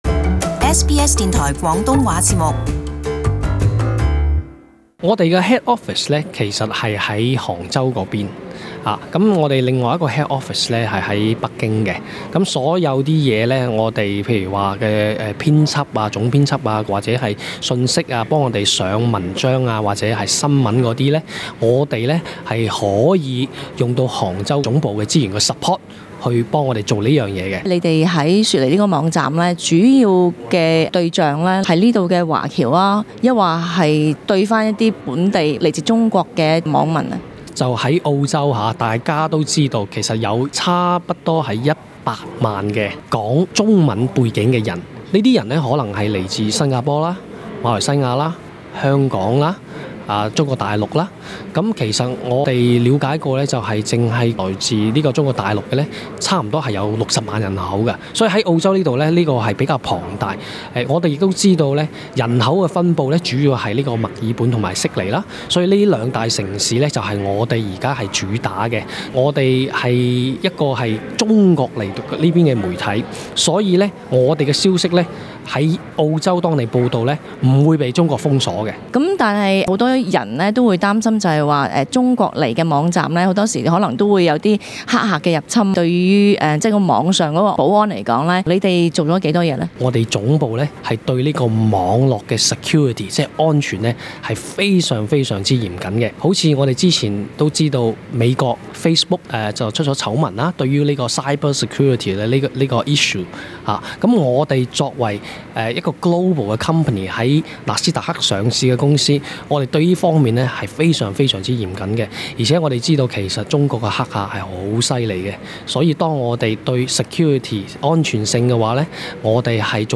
【社区访问】－ 新中文网站『网易』开展雪梨及墨尔本市场